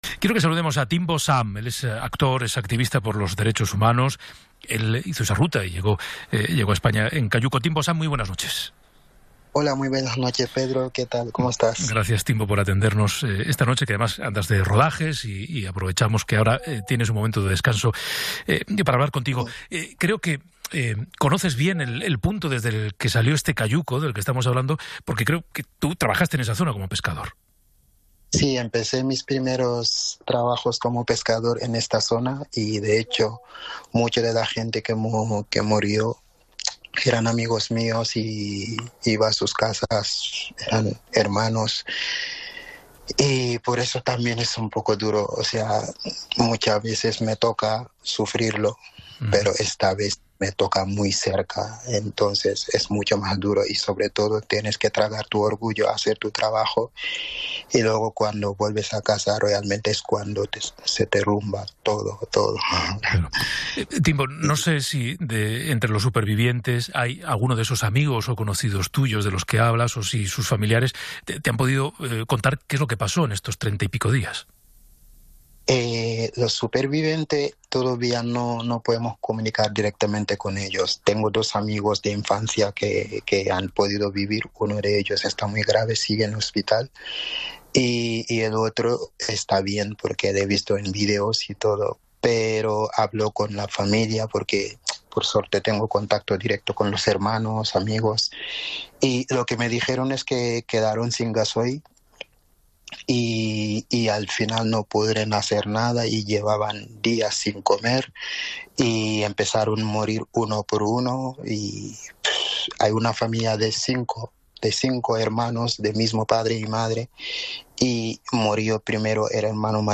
El actor y activista africano ha explicado en Hora 25 la difícil travesía que superó para llegar a España en patera, precisamente horas después de que más de 60 personas hayan fallecido intentando llegar a Canarias en un cayuco.